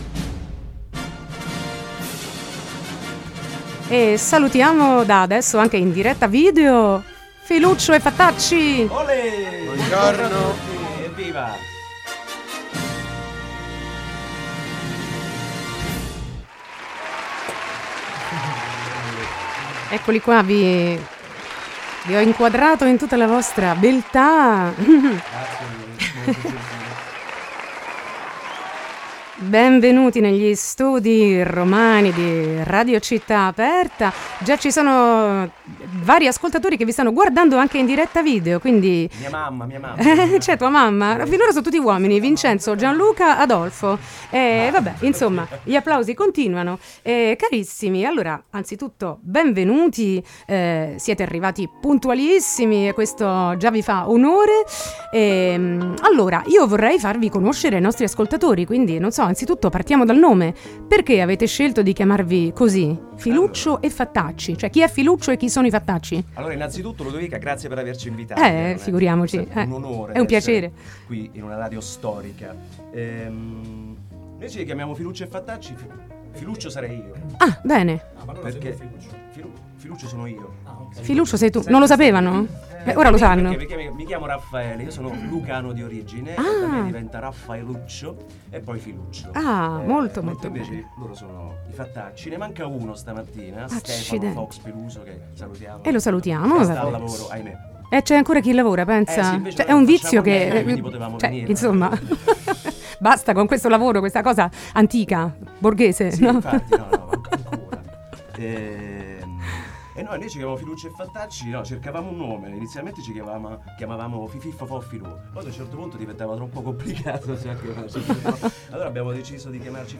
Intervista e minilive Filuccio e Fattacci 31-10-19